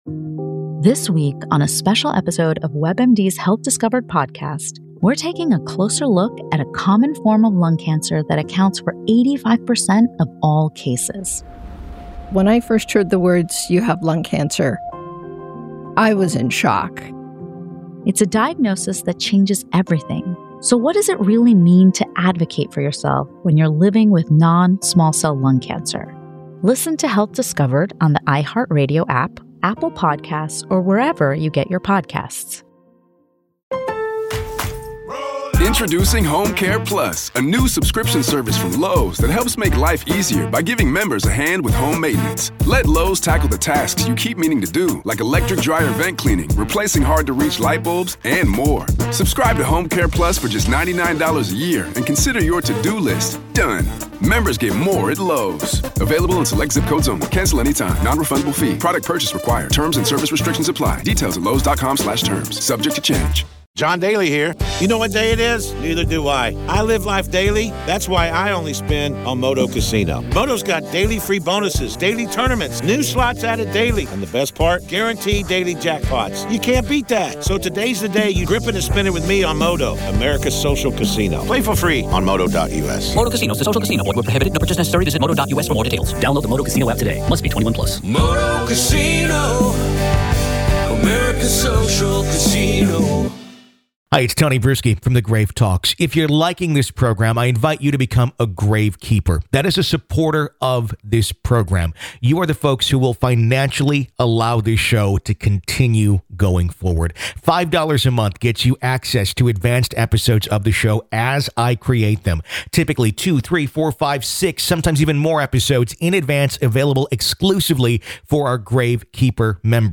In part two of our interview, available only to Grave Keepers, we discuss: Could the spirits that haunt the Anderson Hotel be sad or evil people that have passed and continue to torment the living.